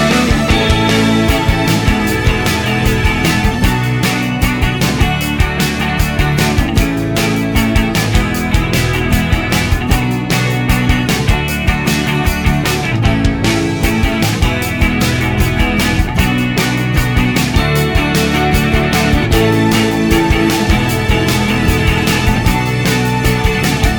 Minus Main Guitar Indie / Alternative 3:25 Buy £1.50